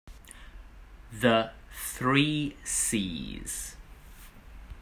音声（イギリス）つきなので、まねして発音してみてください。